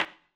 darkwatch/client/public/dice/sounds/surfaces/surface_wood_table5.mp3 at 8da9ac2cf6229fdb804e6bfaca987aa241ffc780
surface_wood_table5.mp3